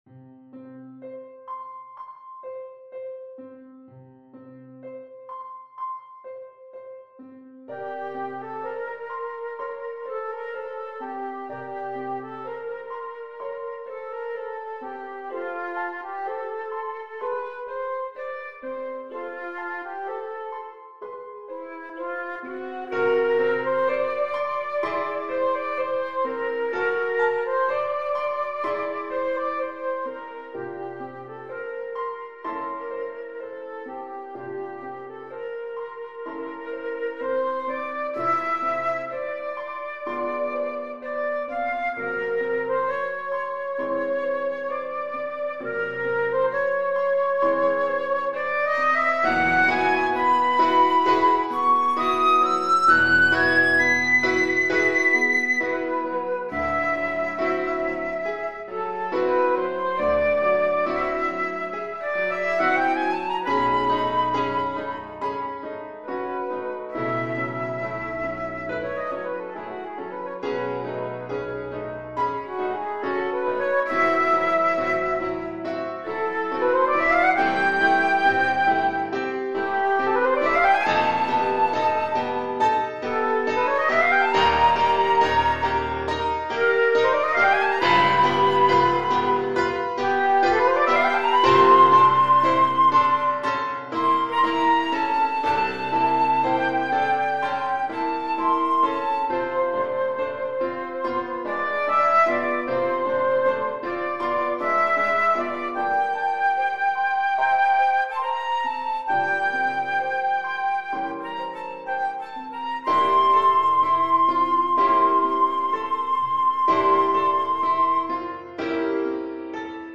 Flute
F major (Sounding Pitch) (View more F major Music for Flute )
Assez lent =63
Classical (View more Classical Flute Music)